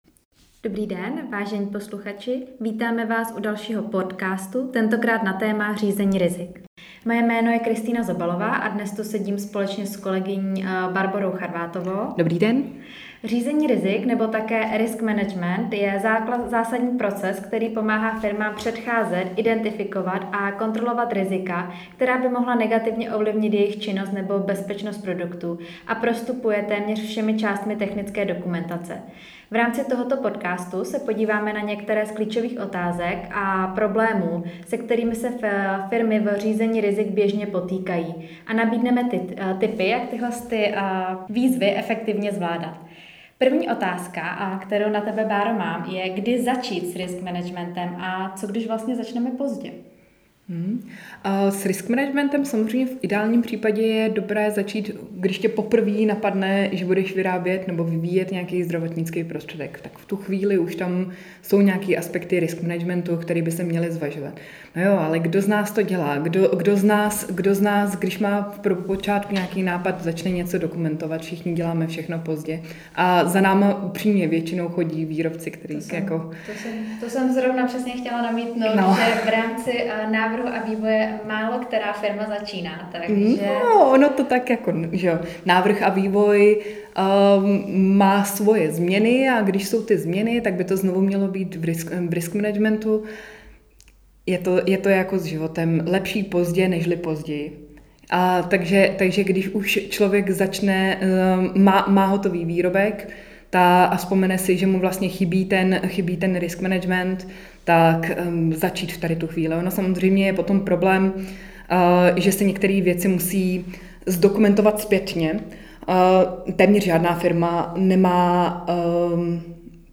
Cílem cca desetiminutového rozhovoru je posluchače stručně seznámit s problematikou risk managementu při posuzování shody zdravotnických prostředků .